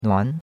nuan2.mp3